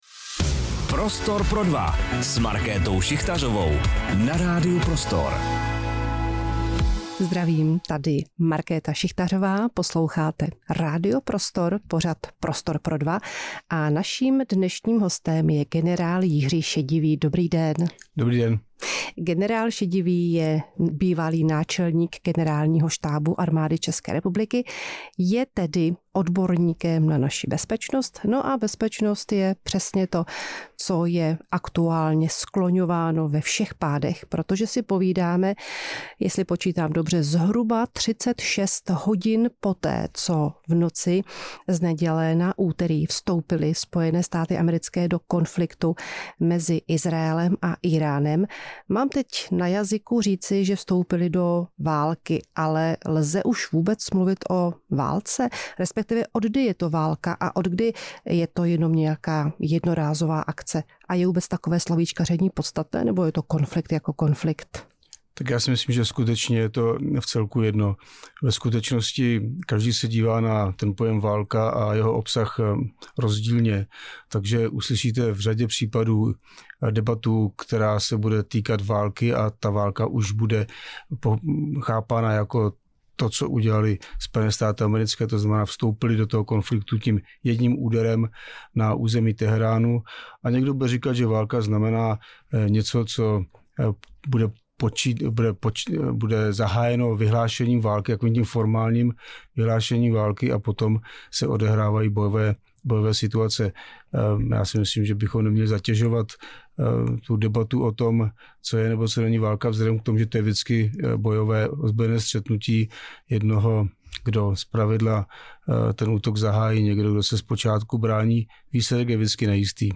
Rozhovor s generálem Jiřím Šedivým | Radio Prostor
Markéta Šichtařová si do Prostoru pro dva tentokrát pozvala generála Jiřího Šedivého, který je zároveň bývalý náčelník generálního štábu AČR. Bavili se spolu například o bezpečnosti a válečných konfliktech.